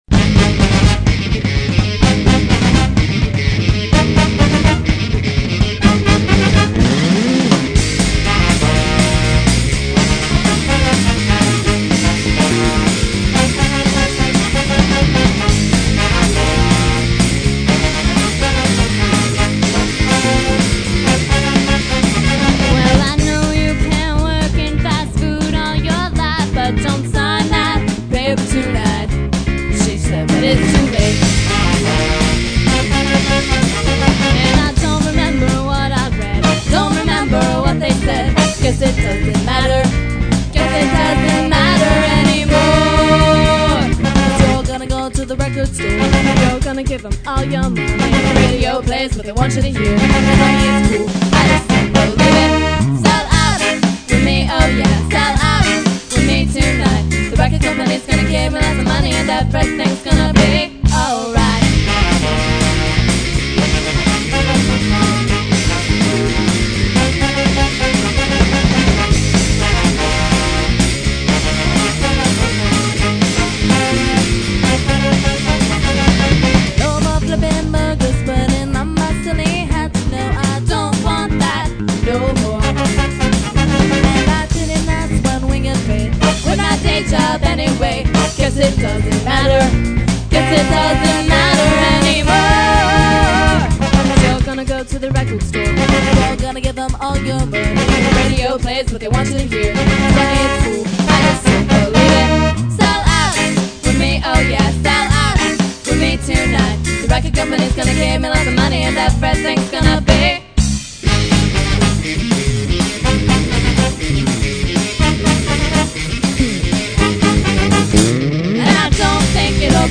Somewhere between ska, rock and pop